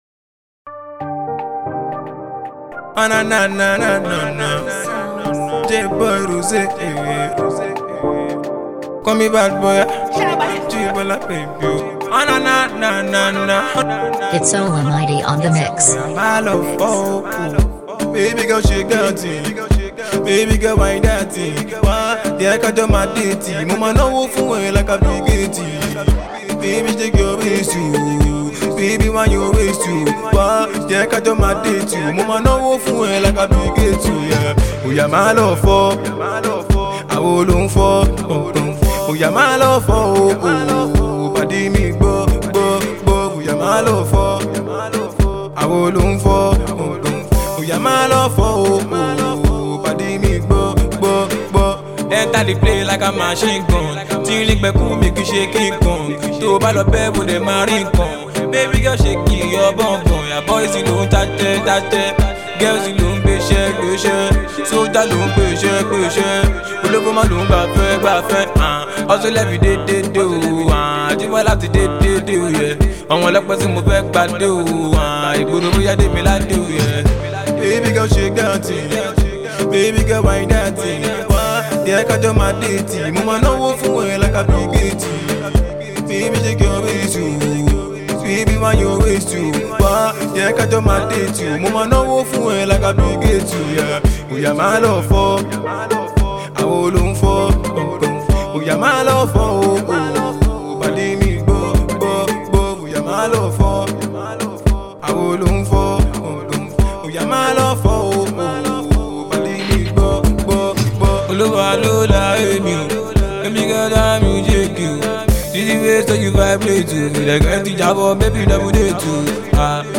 Nigerian talented songwriter, singer/rapper
mixed and mastered